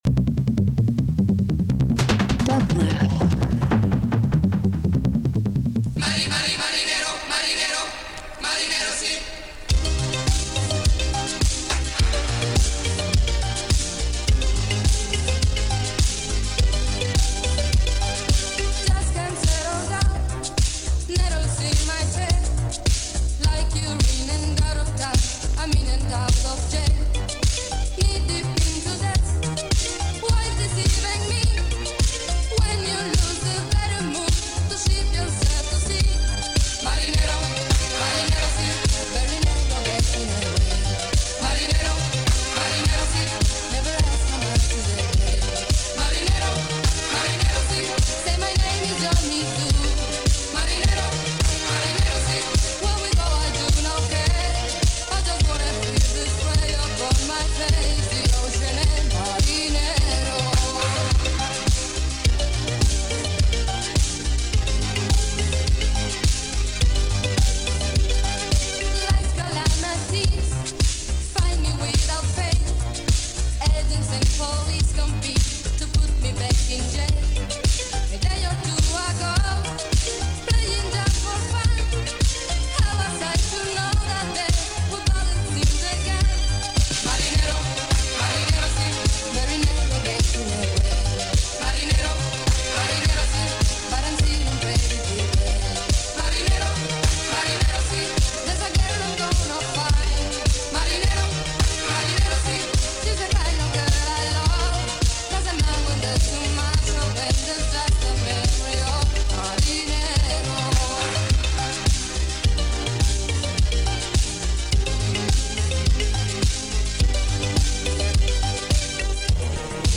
Dance International Latin Pop Psych